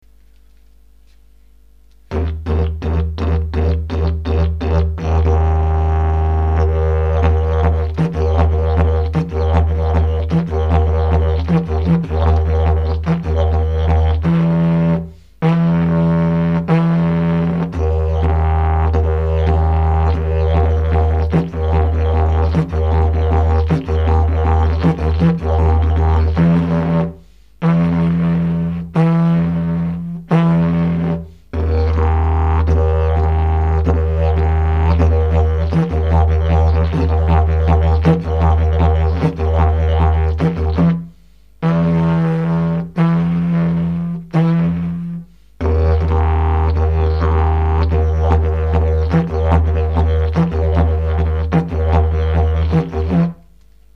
Je trouve ca à la fois dynamique et reposant et les hoot très doux passent pas mal du tout.